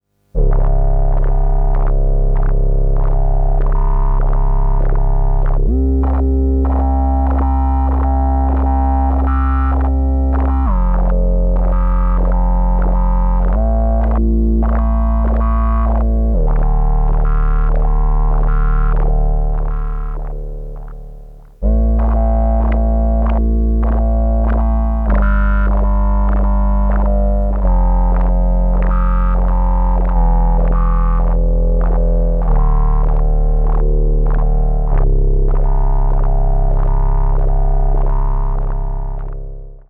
KorgS&H-LPF.flac